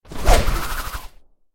Звуки вращения
На этой странице собраны разнообразные звуки вращения: от легкого шелеста крутящихся лопастей до мощного гула промышленных механизмов.